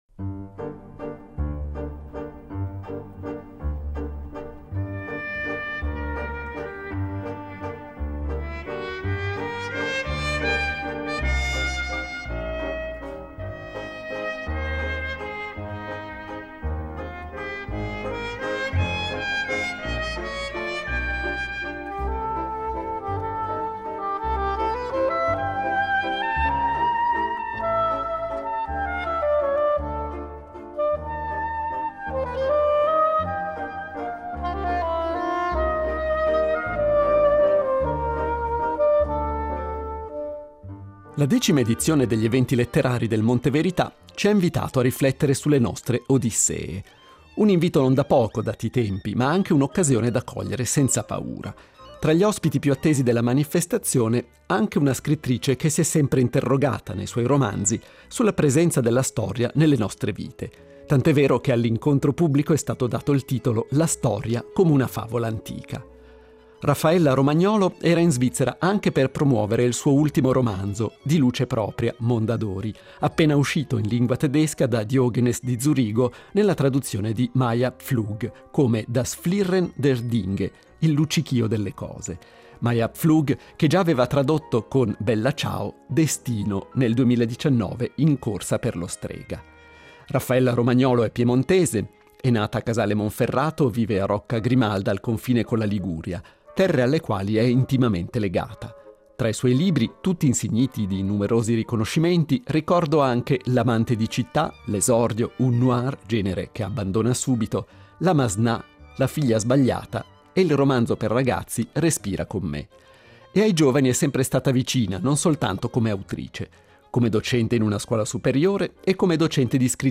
La conversazione si è tenuta in pubblico durante la decima edizione degli Eventi Letterari Monte Verità.